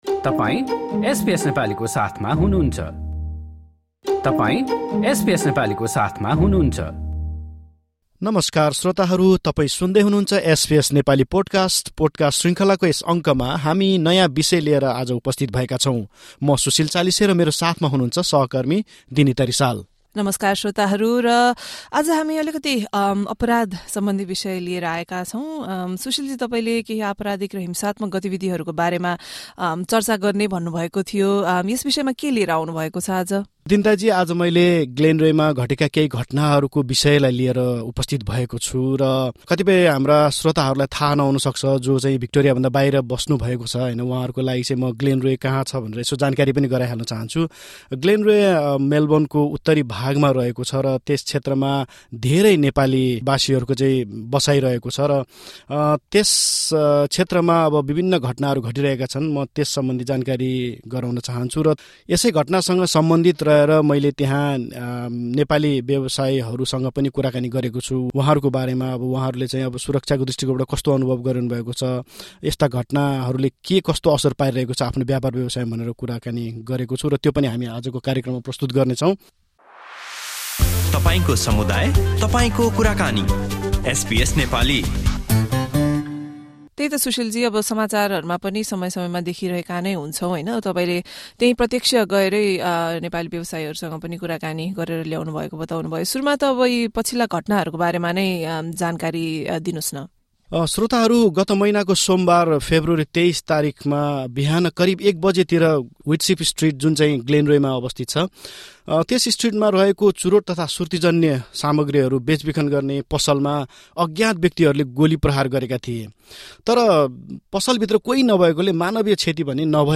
However, recent attacks on two tobacco shops in the suburb’s main shopping precinct have raised concerns amongst the neighbouring local Nepali business owners about their own safety and the security of their businesses. Listen to our report featuring the reactions of some Nepali business owners and their views on the situation.
Nepali business owners in Glenroy, speaking with SBS Nepali.